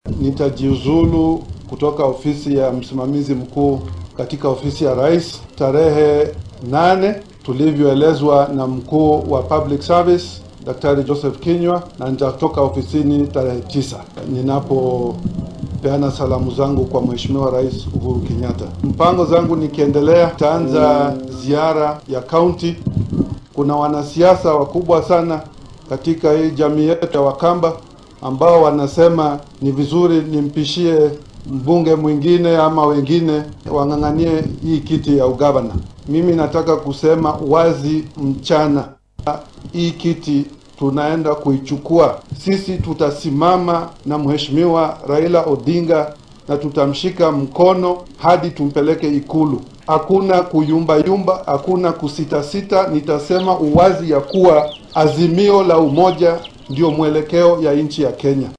Madaxa shaqaalaha ee madaxtooyada dalka looga arrimiyo ee State House ee magaalada Nairobi ,Nzioka Waita ayaa sheegay in lagu cadaadinya inuu ka tanaasulo hamigiisa siyaasadeed ee uu ku doonaya inuu u tartamo xilka barasaabka ee ismaamulka Machakos. Mr. Waita oo warbaahinta kula hadlay dowald deegaanka Machakos ayaa sheegay in siyaasiyiin u magacaabin ee kasoo jeeda gobolka bari ee dalka ee Ukambani ay ugu baaqeen in go’aankiisa uu ku doonaya inuu isu sharraxo xilka guddoomiyaha Machakos uu ka laabto.